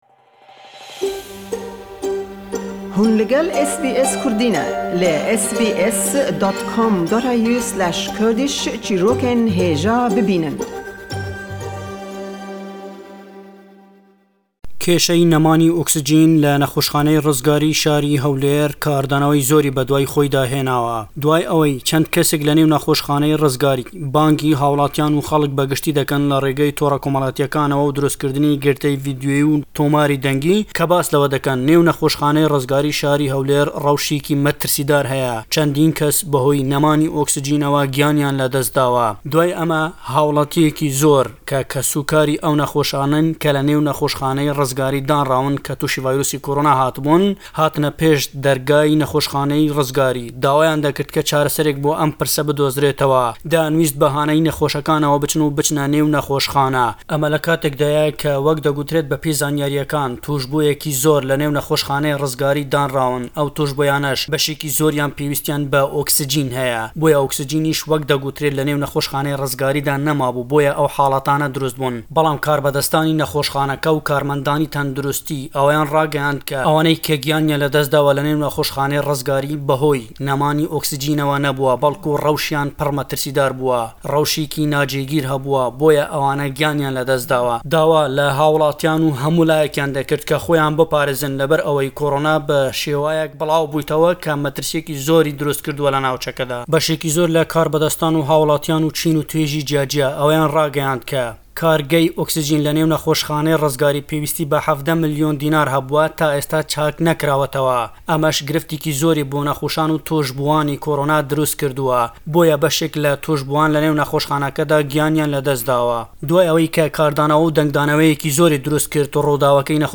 Le em raporte da le Hewlêre we, diwa rewşî amarekanî vîrusî-korona le Herêmî Kurdistan, ke le hellkêşan da ye le Hewlêr û Duhok, cend babetî dîke...